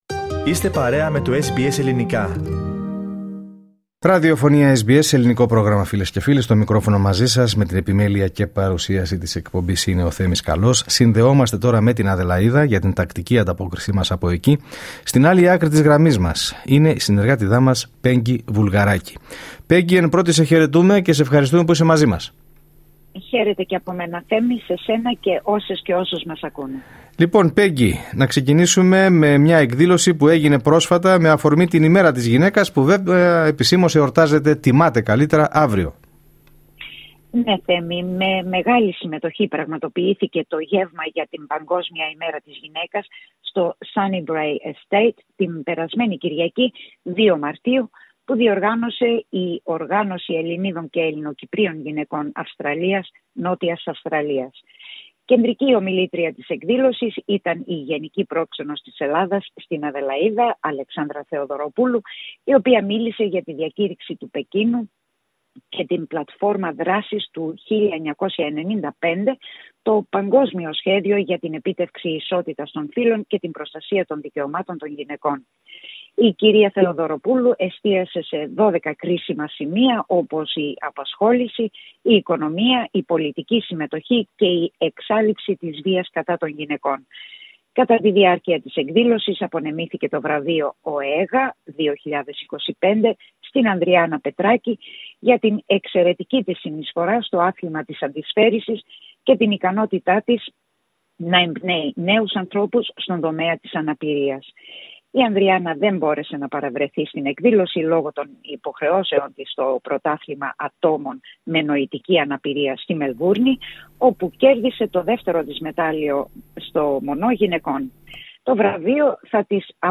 Ακούστε την εβδομαδιαία ανταπόκριση από την Αδελαΐδα